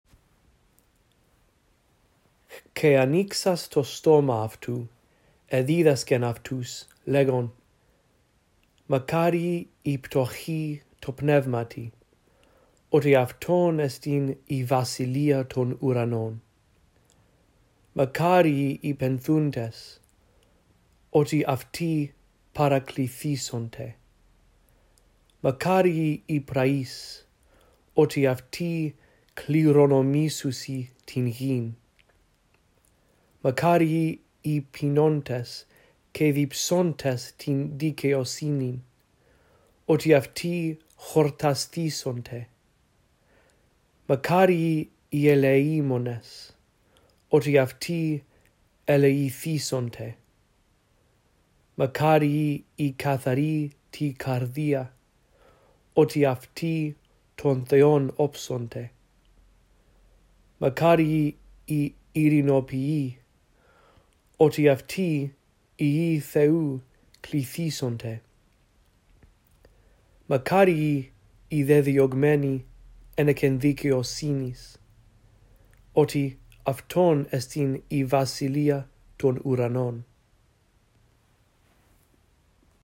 1. Listen to me read all of Matthew 5:2-10, following along in the text below.